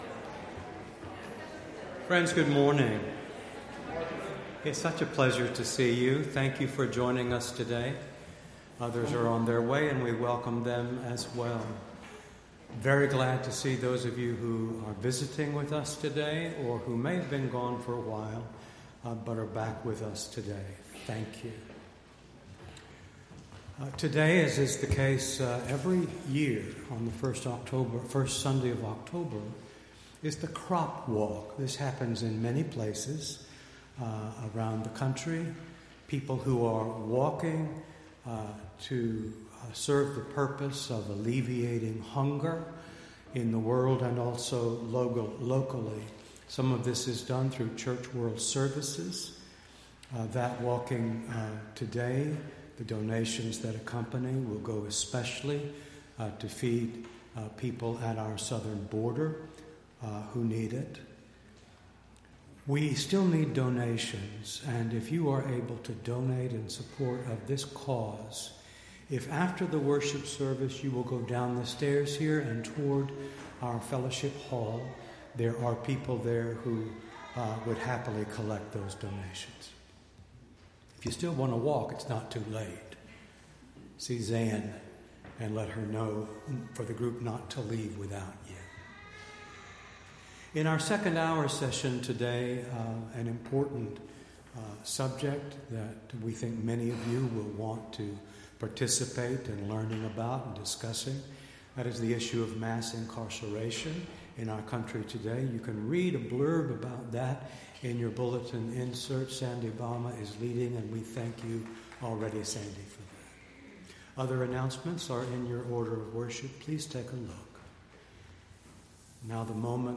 Entire October 6th Service